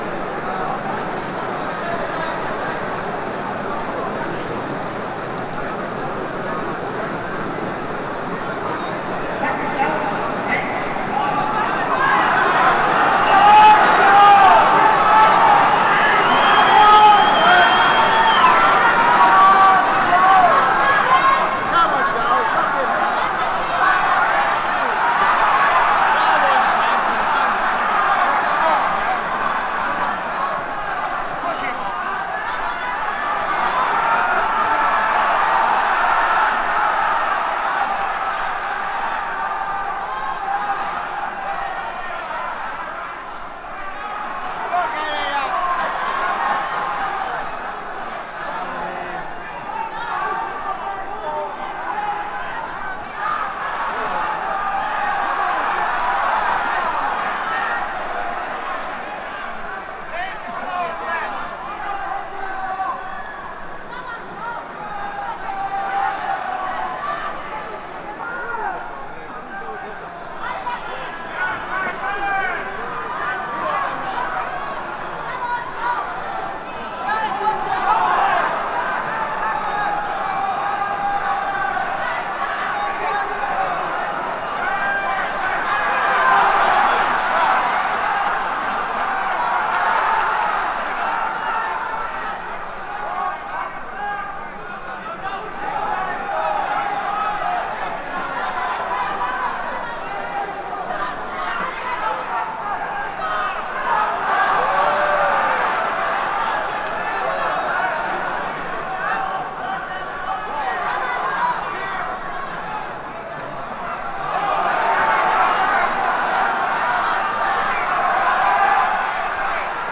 fight-yells-2.mp3